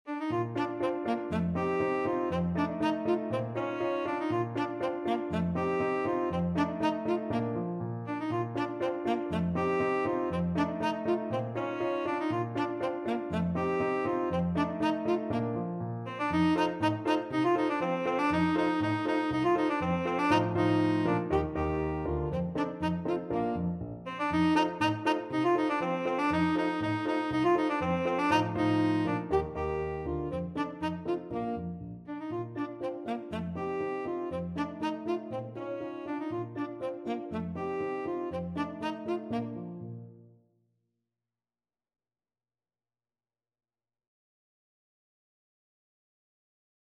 Tenor Saxophone
2/4 (View more 2/4 Music)
Allegro (View more music marked Allegro)
Bb major (Sounding Pitch) C major (Tenor Saxophone in Bb) (View more Bb major Music for Tenor Saxophone )
Classical (View more Classical Tenor Saxophone Music)
ecossaise_beethoven_TSAX.mp3